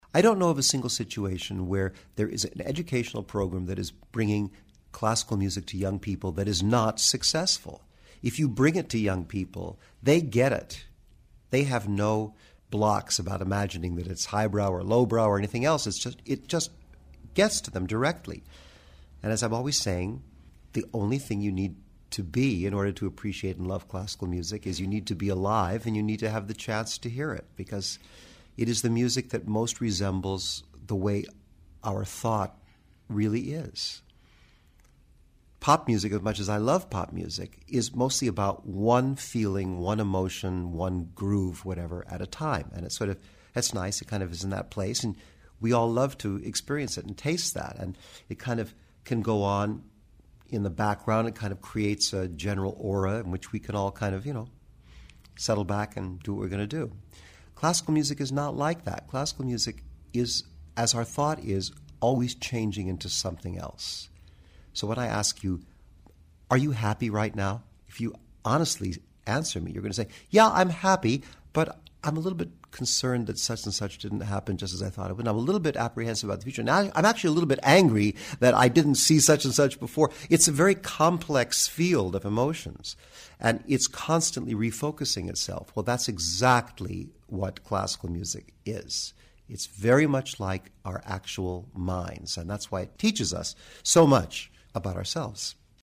In this excerpt from the podcast, Tilson Thomas discusses the vitality of classical music, and the importance of arts education in maintaining its vibrancy.